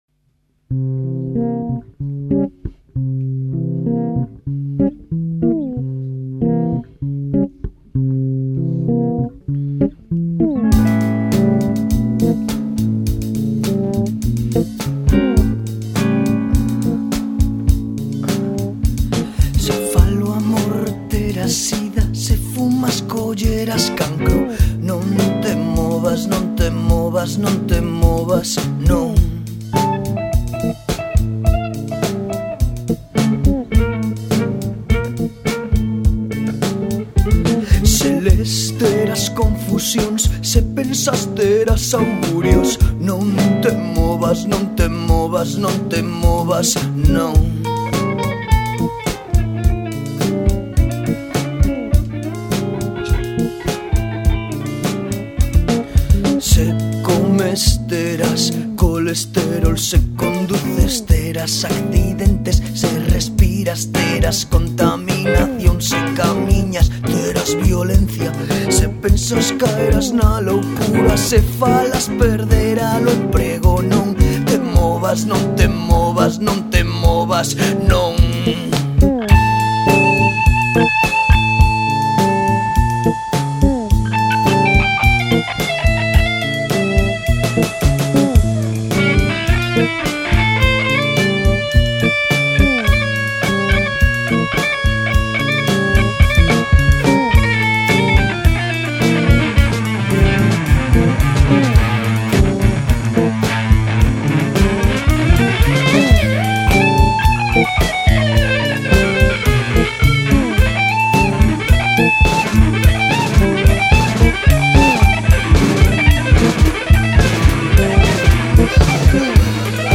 cuarteto